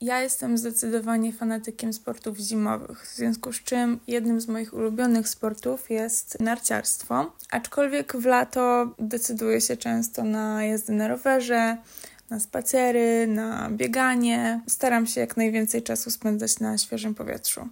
Mieszkańcy Opola opowiedzieli nam, jaką formę aktywności fizycznej preferują najbardziej: